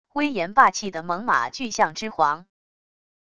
威严霸气的猛犸巨象之皇wav音频